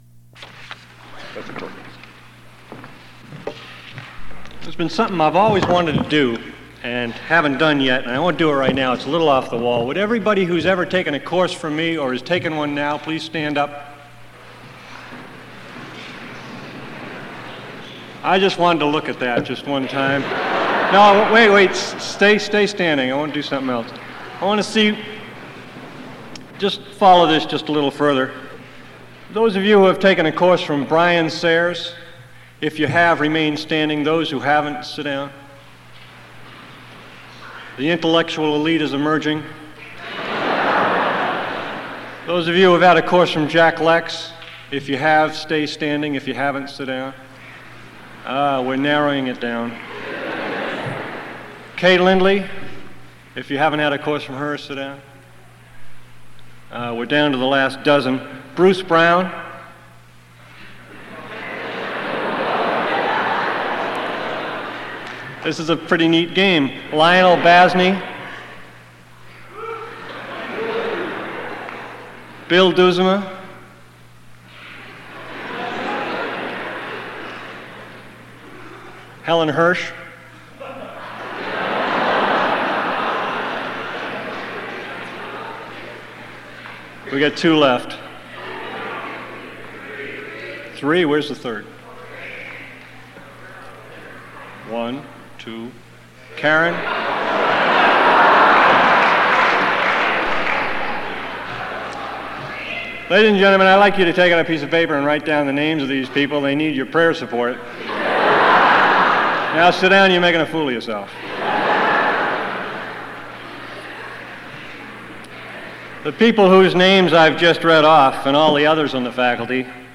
Bible Conference